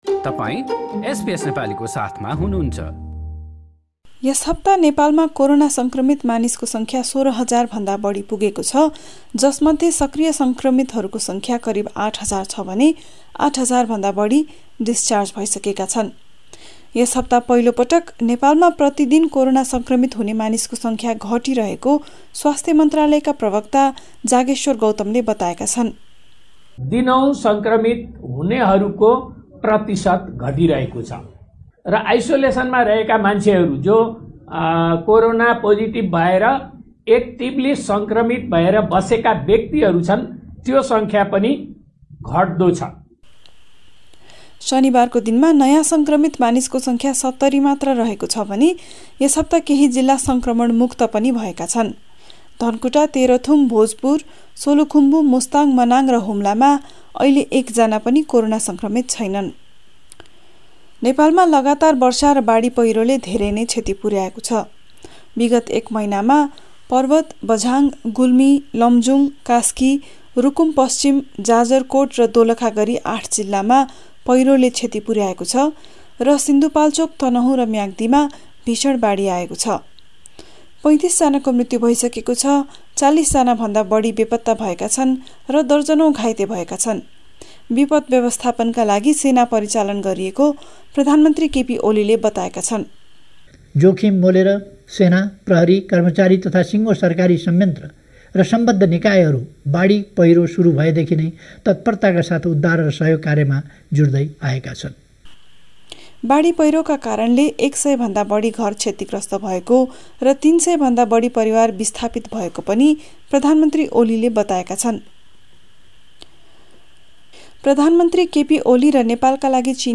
यस हप्ता पहिलोपटक कोरोनाभाइरस बाट सङ्क्रमित हुनेहरुको दैनिक संख्या घटेको स्वास्थ्य अधिकारीहरुको भनाइ र विगत एक महिनामा बाढी र पहिरोका कारण दर्जनौंको मृत्यु र ठुलो सङ्ख्यामा मानिसहरु अझै बेपत्ता रहेको लगायत गत ७ दिनका प्रमुख नेपाल समाचार यहाँ सुन्नुहोस्।